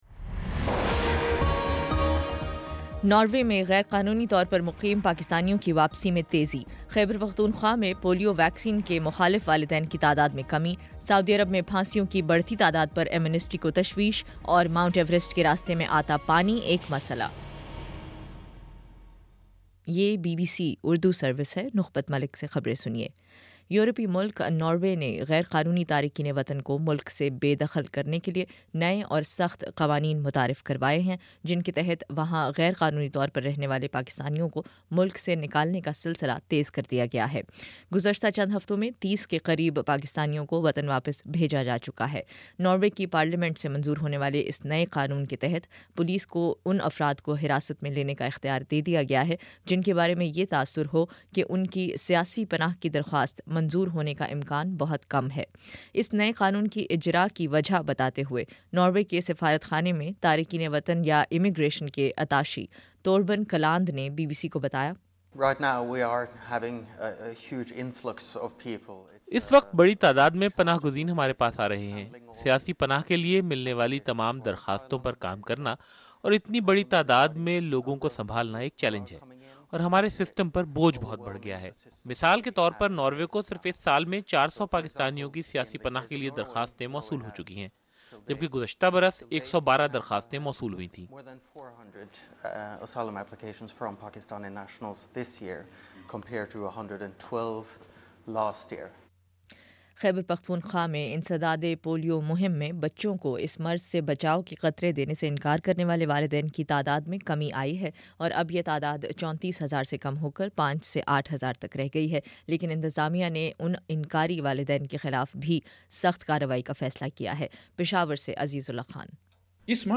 نومبر 27 : شام پانچ بجے کا نیوز بُلیٹن